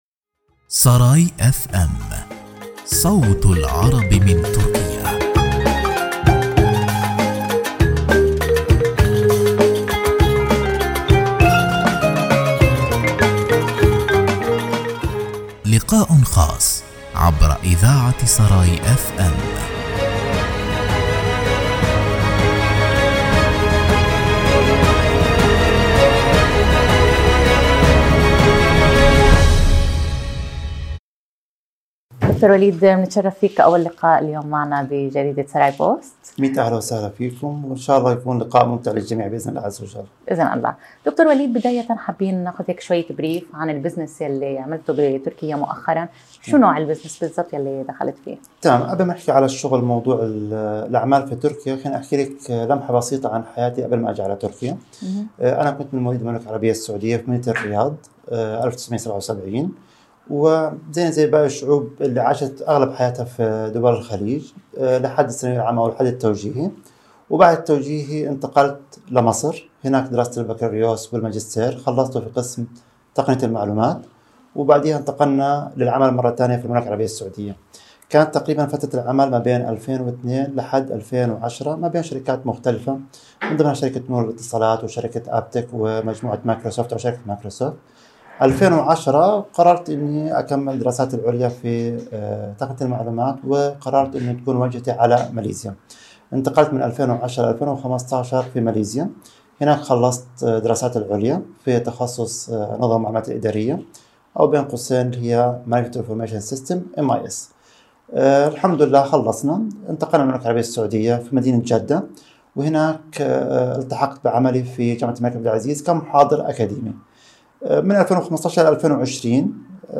للإستماع للقاء كاملاً: